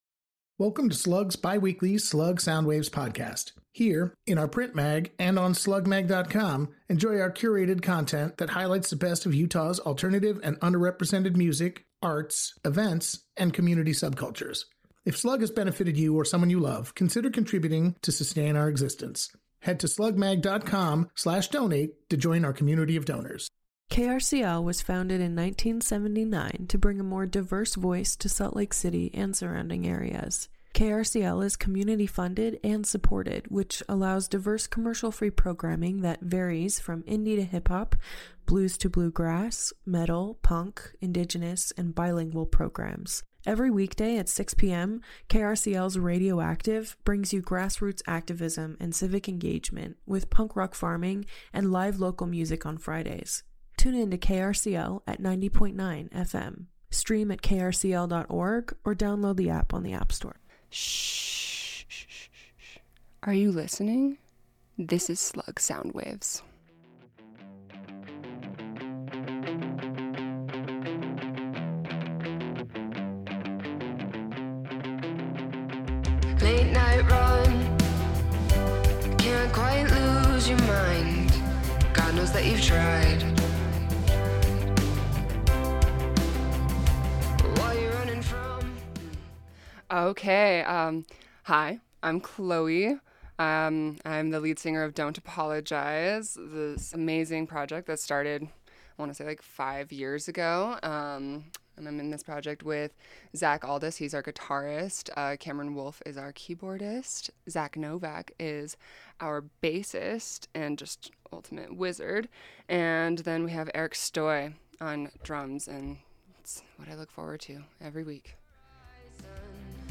alt-rock